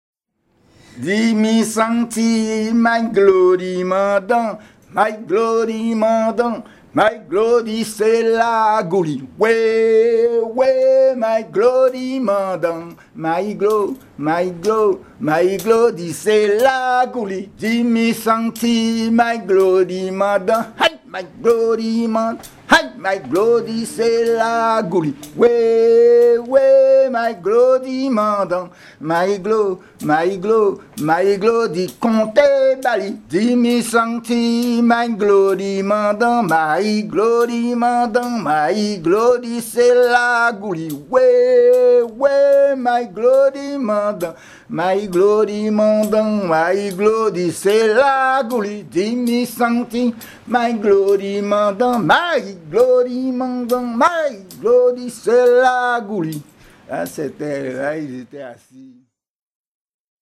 Grand-Bourg ( Plus d'informations sur Wikipedia ) Guadeloupe
Genre laisse
Pièce musicale éditée